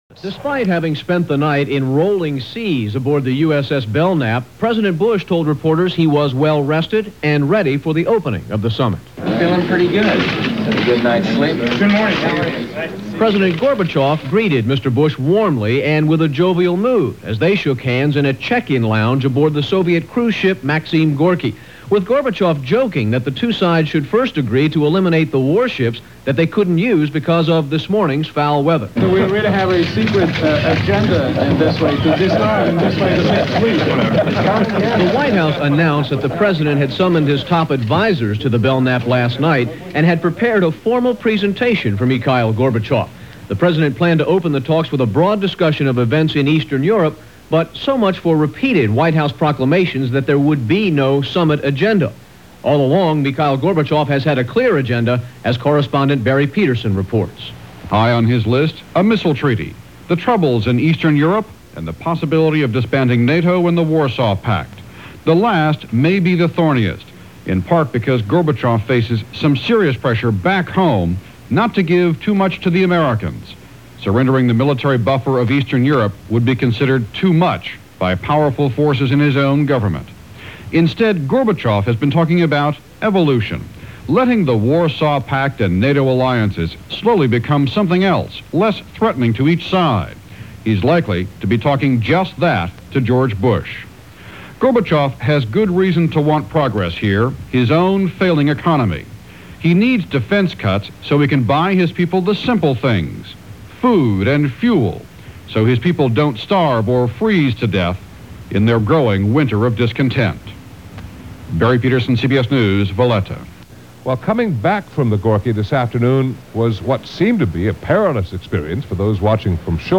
News on the Summit meeting between President Bush And Mikhail Gorbachev on Malta.
December 2, 1989 – News – Special Reports – Malta Summit